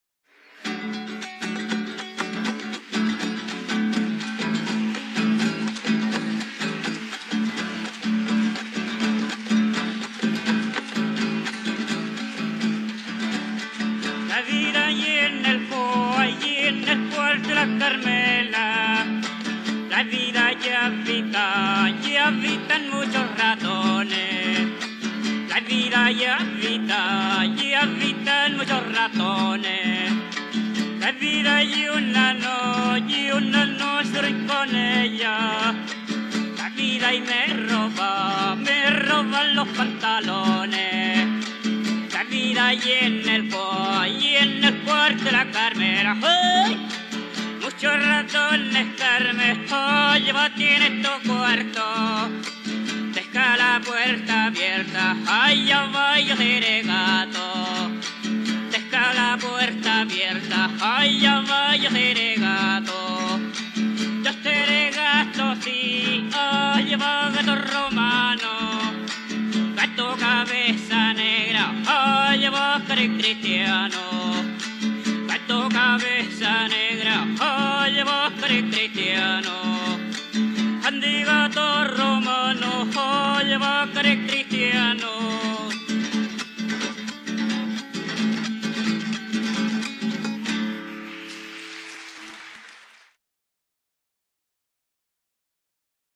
Cueca
acompañado de guitarra afinada con la tercera alta.
Música tradicional
Folklore